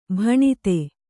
♪ bhaṇite